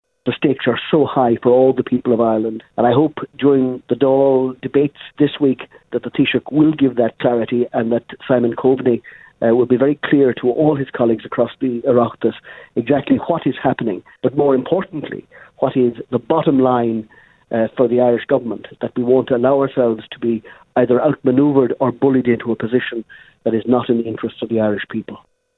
Labour Party Leader Brendan Howlin says the prospect of no-deal is in danger of becoming a frightening reality – and is calling for clarity from the Taoiseach: